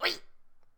capri_whee2.ogg